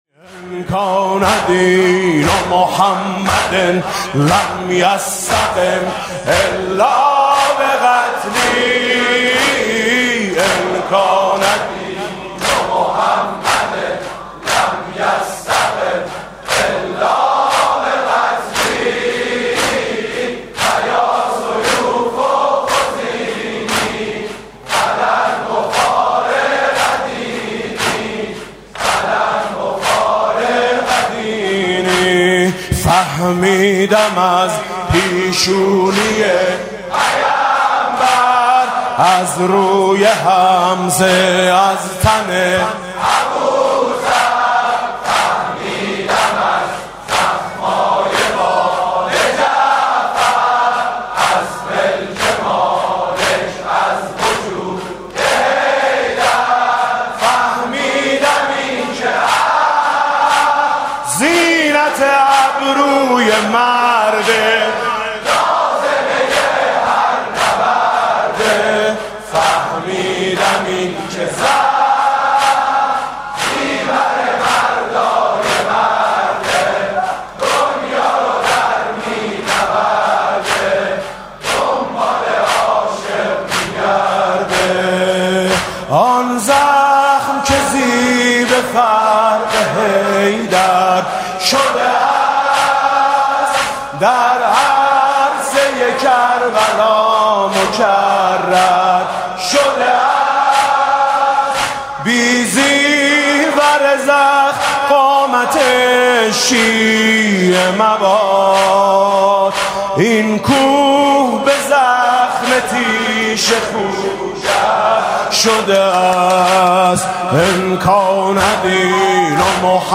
دم پایانی